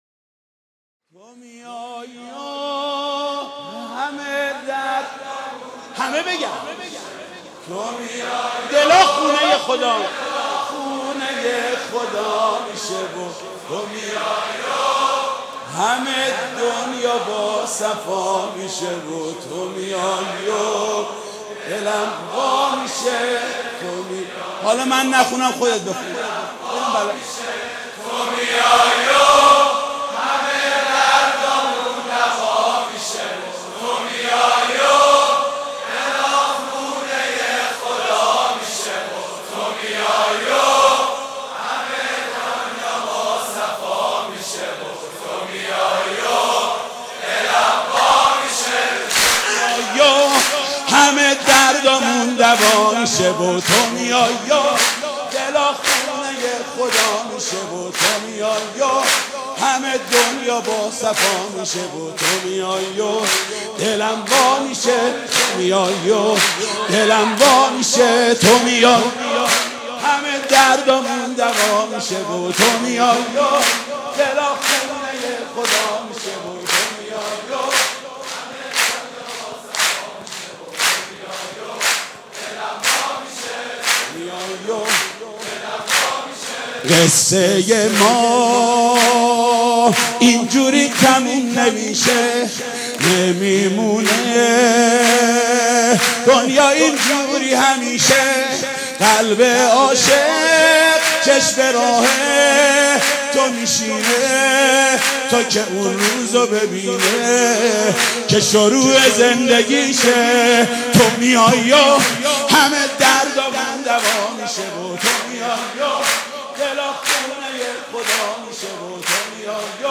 سرود میلاد امام زمان(عج)/ تو می‌آیی و ....